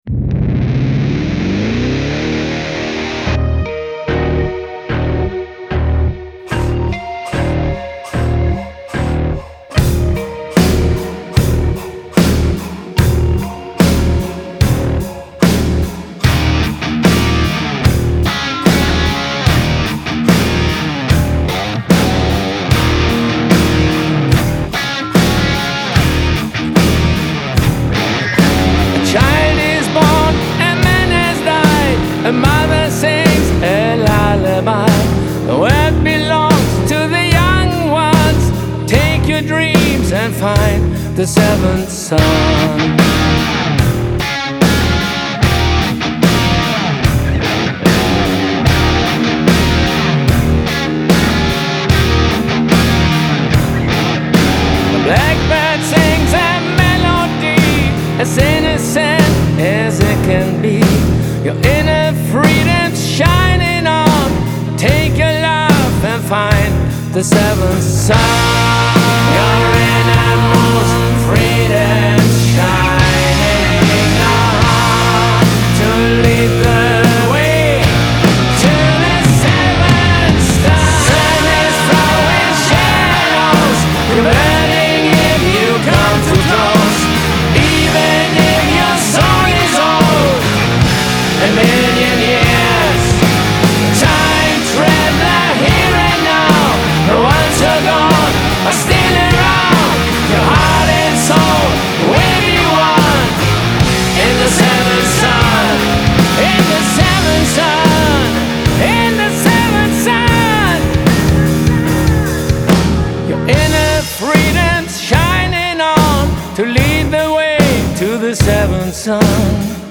Genre : Hard Rock